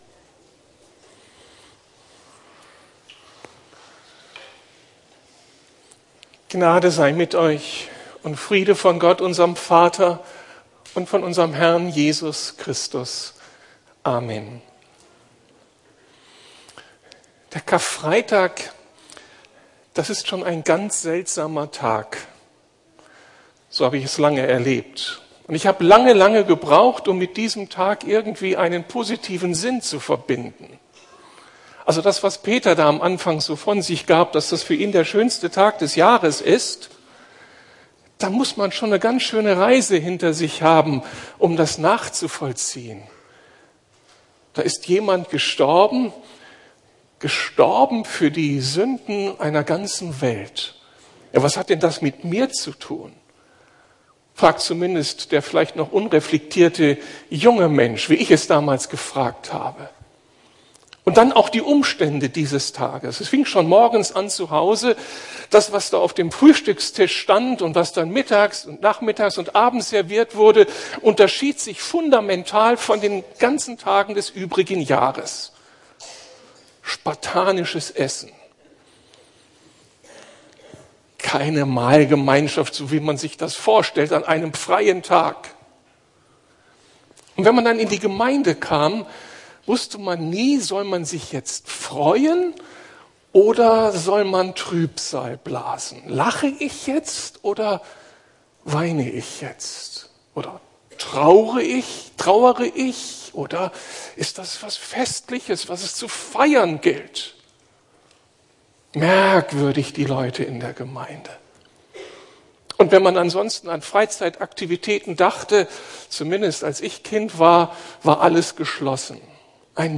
Der andere Blick auf das Kreuz Jesu ~ Predigten der LUKAS GEMEINDE Podcast